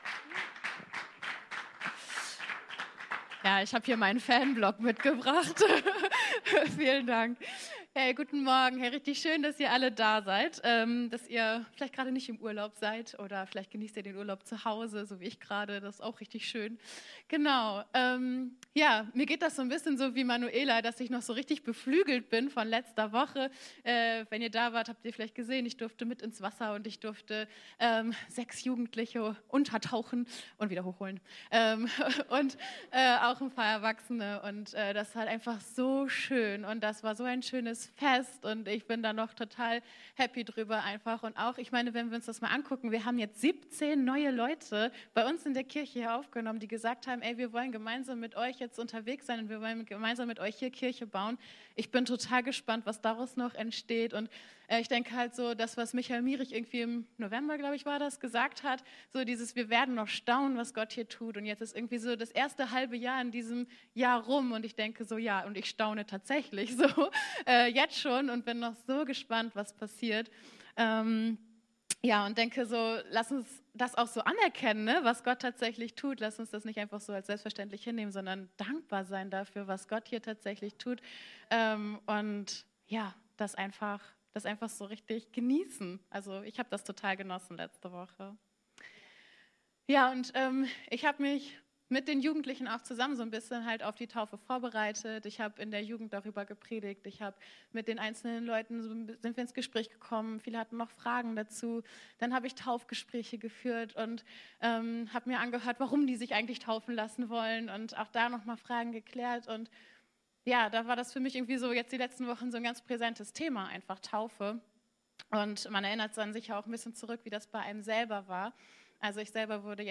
Weitere Predigten